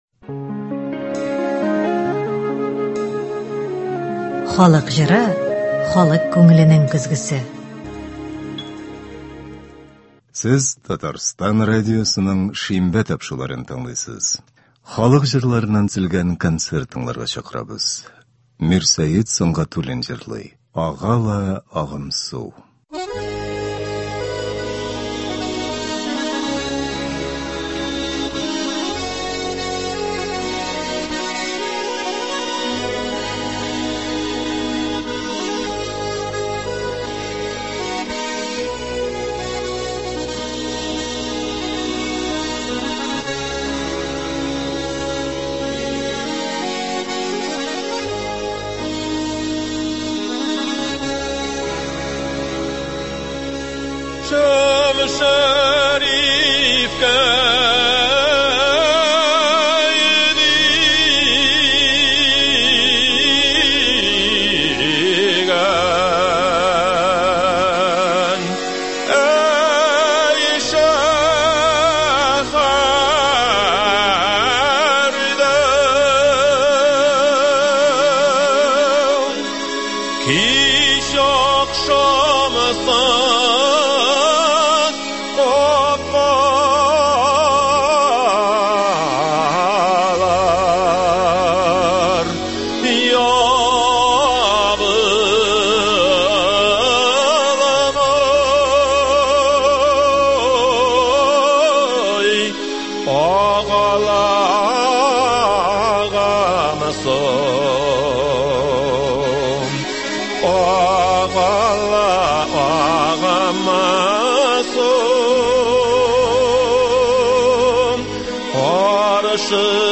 Татар халык көйләре (12.01.24)
Бүген без сезнең игътибарга радио фондында сакланган җырлардан төзелгән концерт тыңларга тәкъдим итәбез.